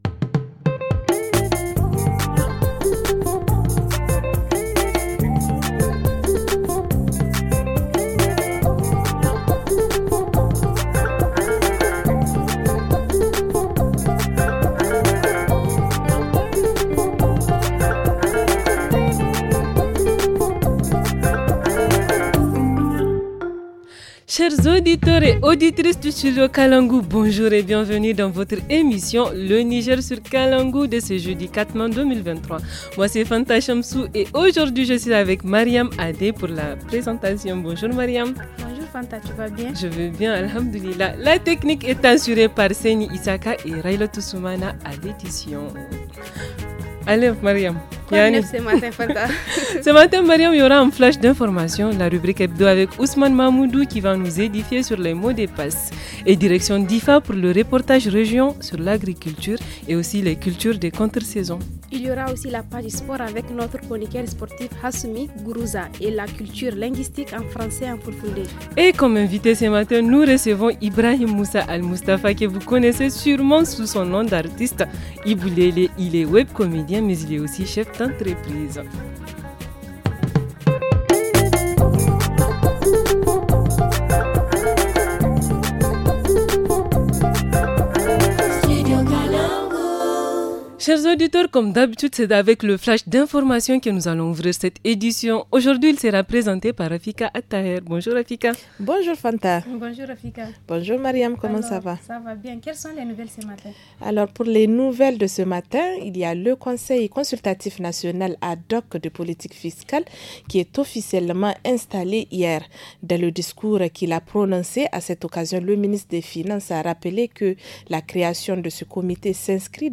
– Le reportage à Diffa sur les aménagements hydroagricoles ;